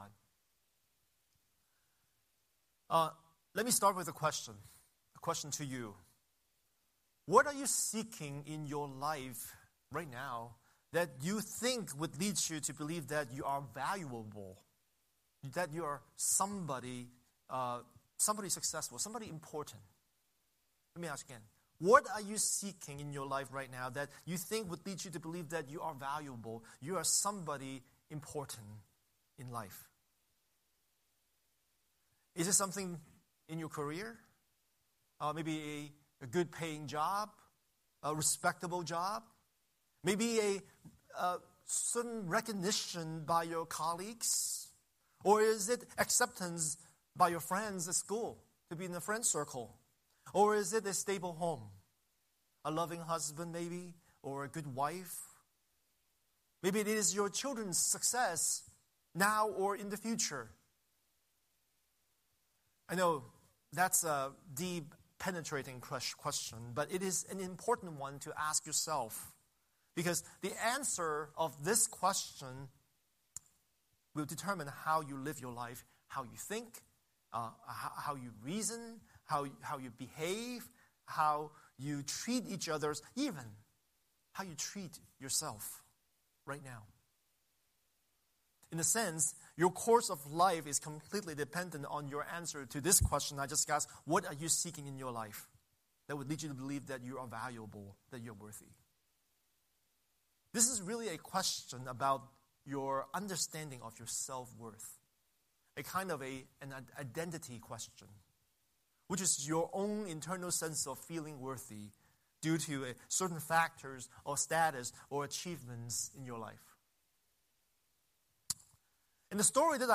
Scripture: Genesis 29:31–30:24 Series: Sunday Sermon